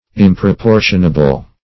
Search Result for " improportionable" : The Collaborative International Dictionary of English v.0.48: Improportionable \Im`pro*por"tion*a*ble\, a. Not proportionable.